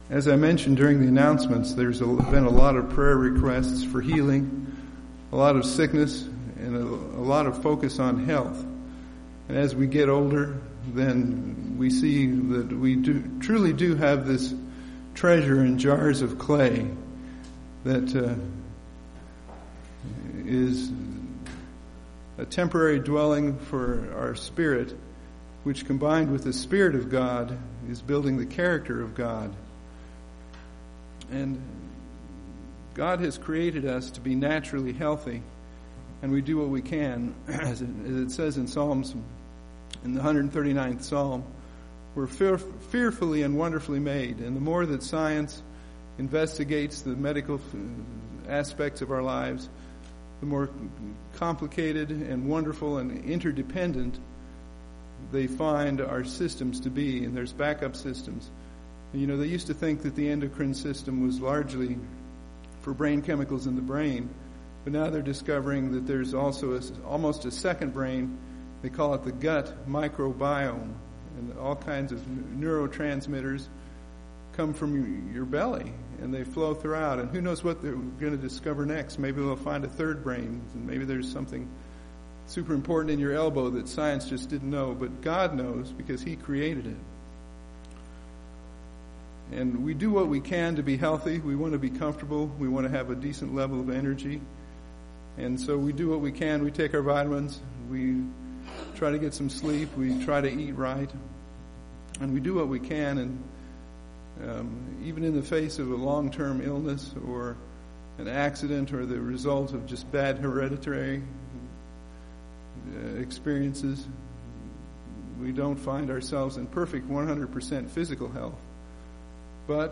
This sermon explores four parallels between physical health and spiritual health.